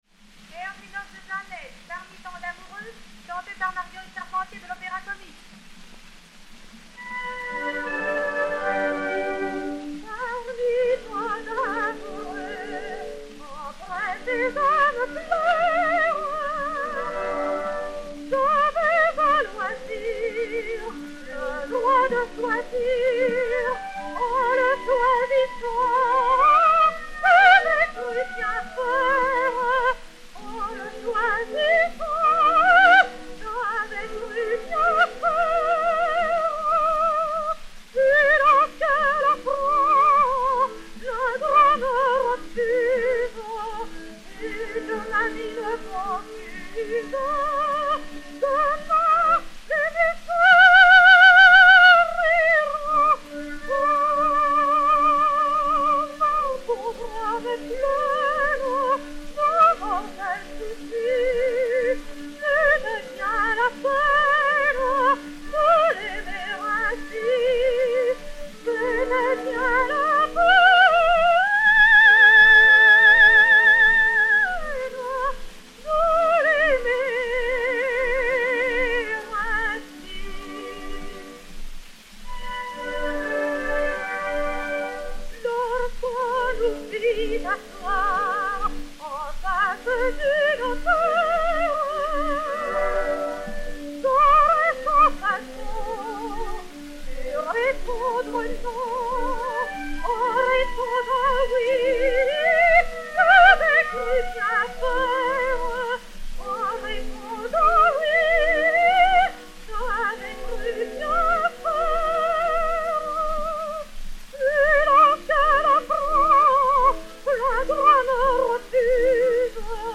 soprano de l'Opéra-Comique Orchestre
et Orchestre
Pathé saphir 90 tours n° 525, réédité sur 80 tours n° 132, enr. en 1911